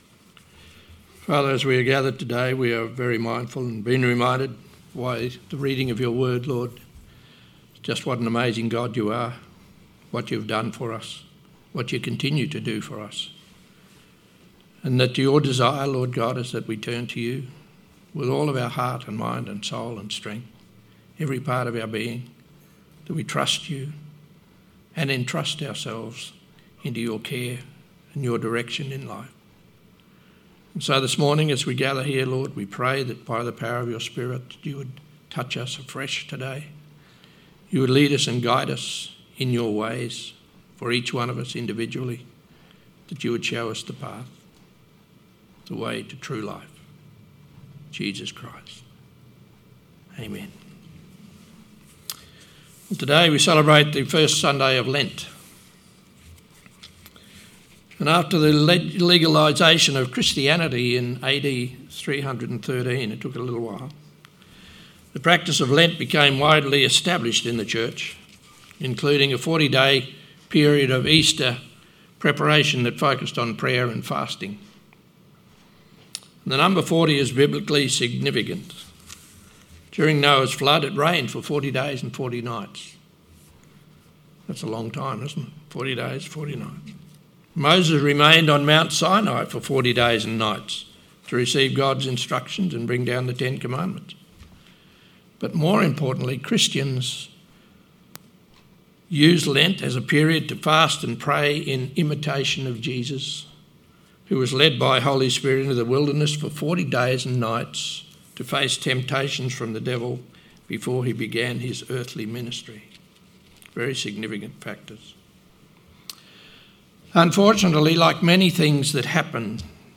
Sermon 22nd February 2026